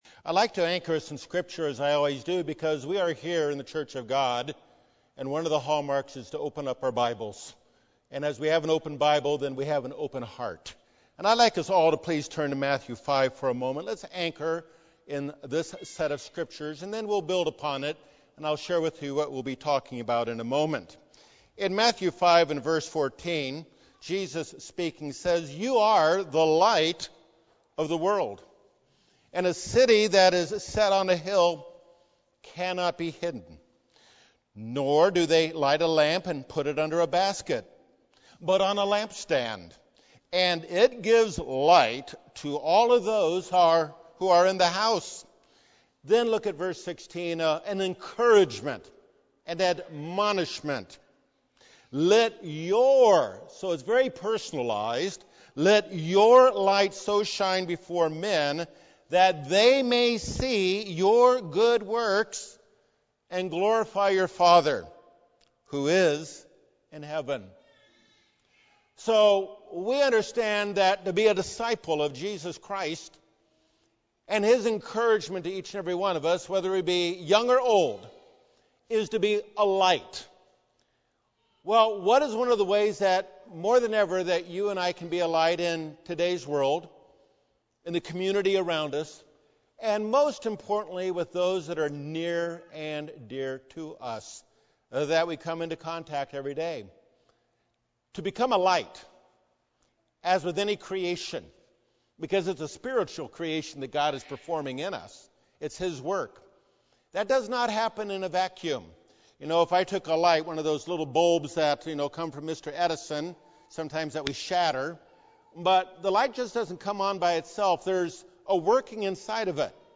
How does a disciple of Jesus Christ follow His statement to be a light to others by how we express ourselves regarding life's challenges with those closest to us? This practical Christianity #101 message offers biblical examples and steps to move beyond feelings and create Spirit-led decisions that glorify God and serve others.